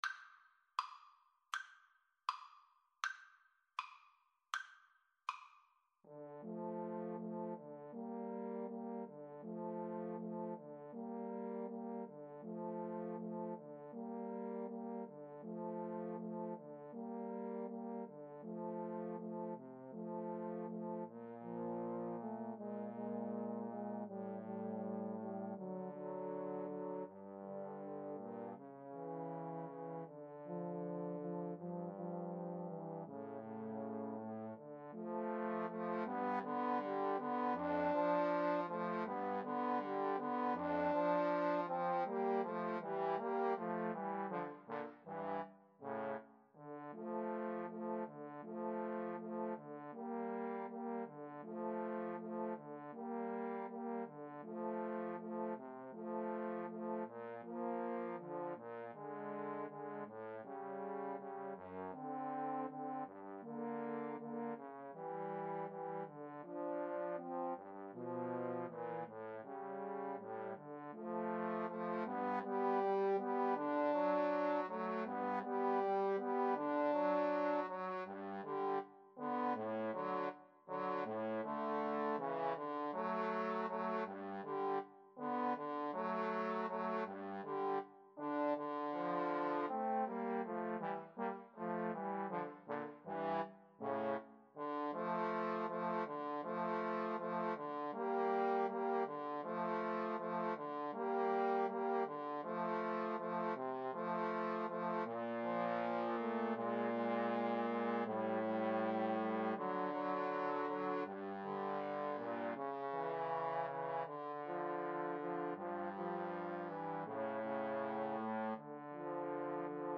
~ = 100 Andante